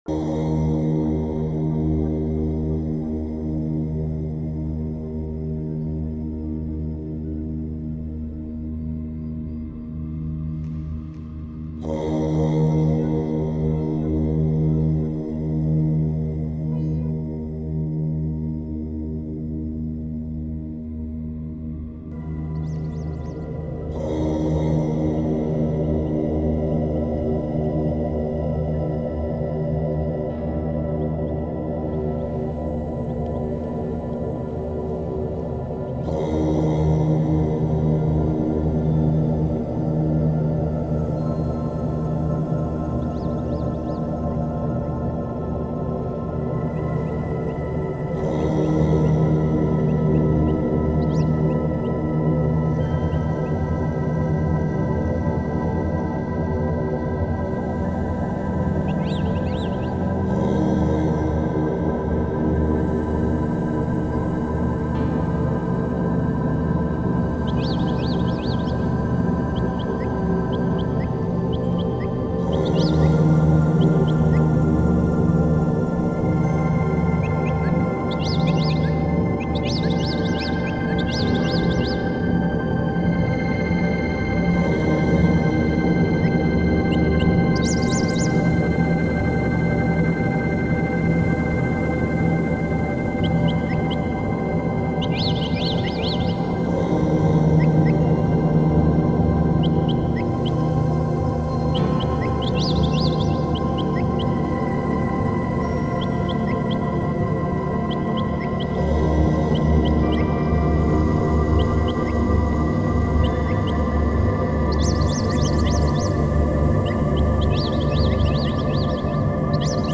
La méditation au son du bol tibétain amène un climat de paix
CHANTS TIBÉTAINS
bolt-Tibetain-meditation-relaxante-spirituelle.mp3